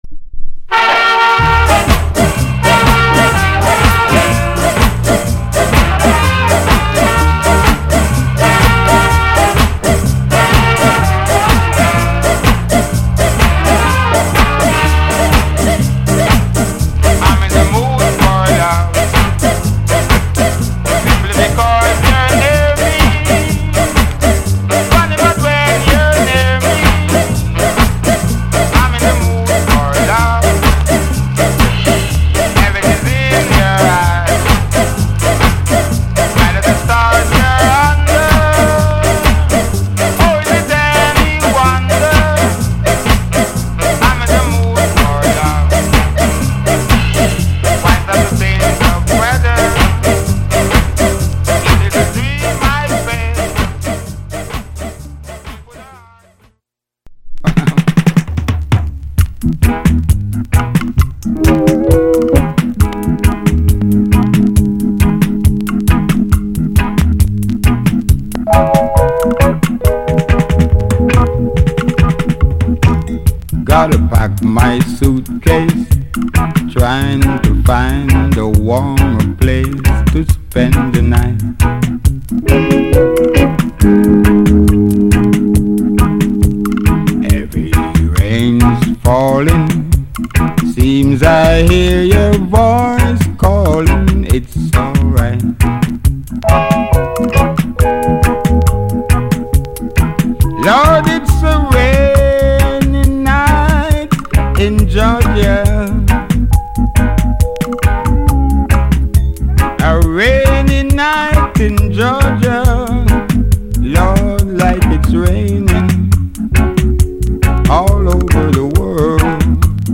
* スカ時代にも歌った名曲をまたもやカヴァー。